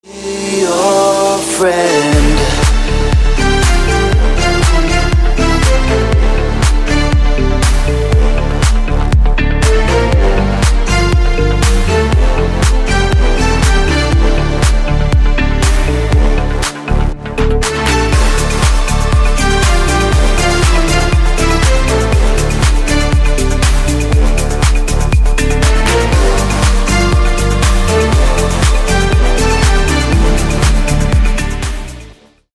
• Качество: 256, Stereo
dance
Electronic
club
Melodic